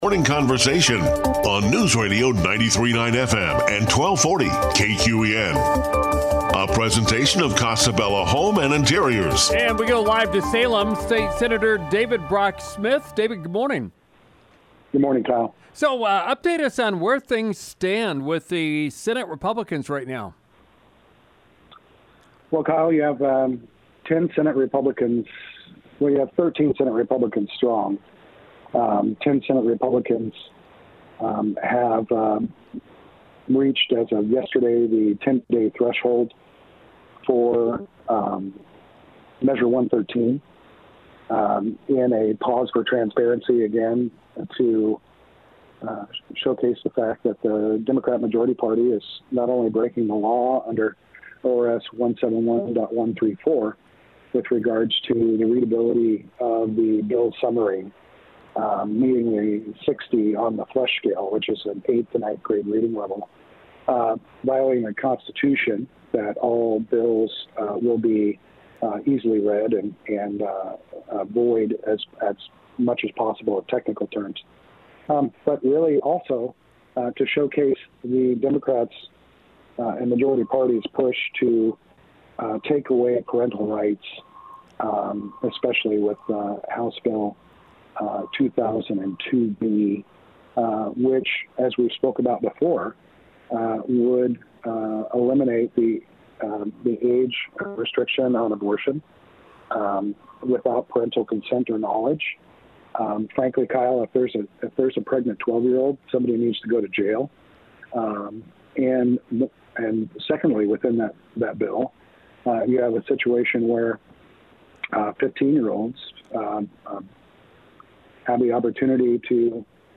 Live from Salem, State Senator David Brock Smith updates us on the situation with Senate Republicans, and related topics.